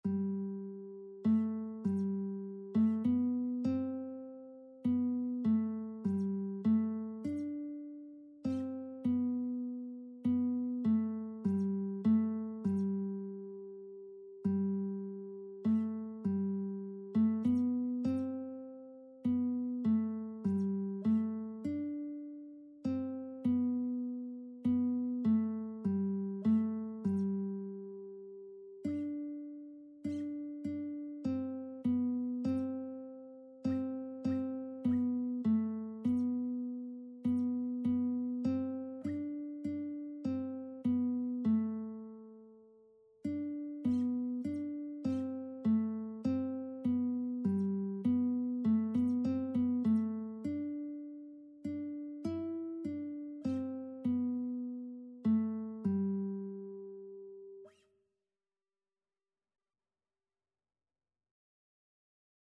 Christian Christian Lead Sheets Sheet Music Alleluia, Sing to Jesus, Hyfrydol
3/4 (View more 3/4 Music)
G major (Sounding Pitch) (View more G major Music for Lead Sheets )
Classical (View more Classical Lead Sheets Music)